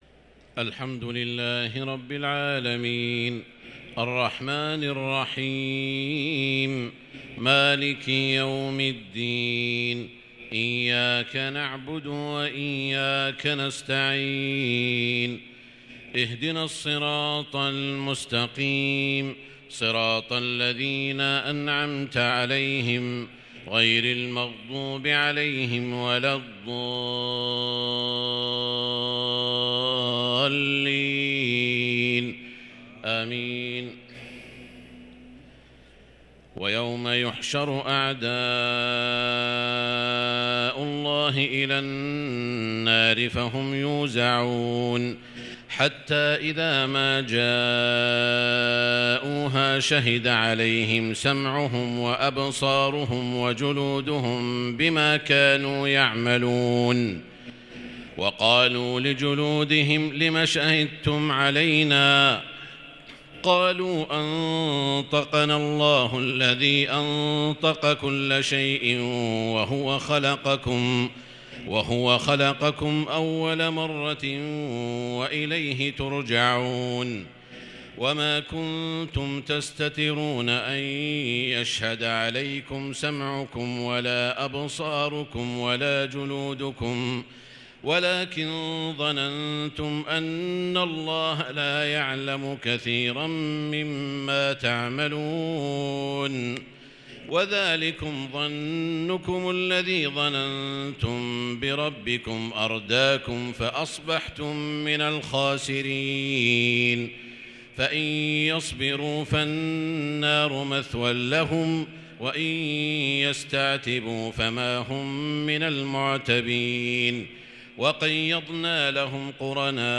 صلاة التراويح ليلة 26 رمضان 1443 للقارئ سعود الشريم - الثلاث التسليمات الاولى صلاة التهجد